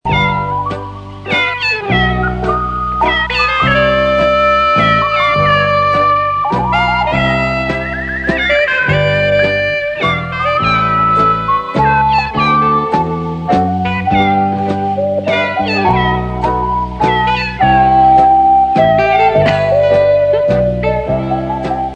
sing along with the score.